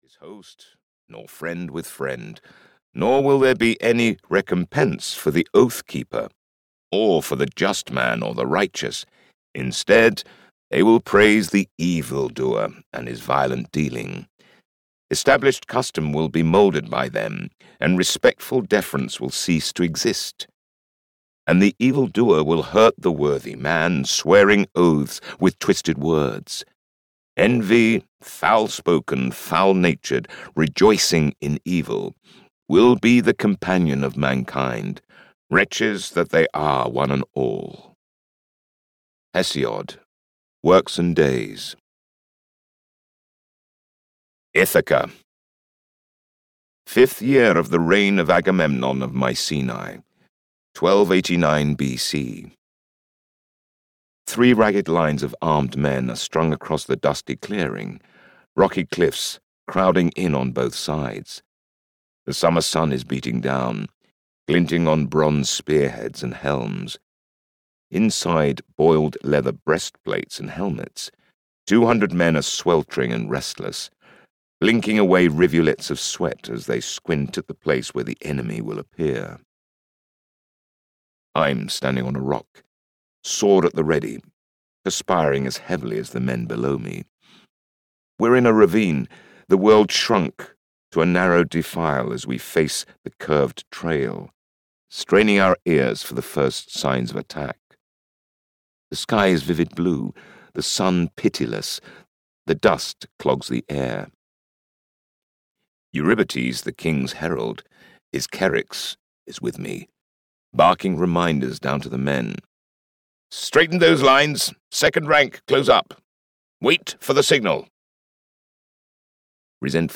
Oracle's War (EN) audiokniha
Ukázka z knihy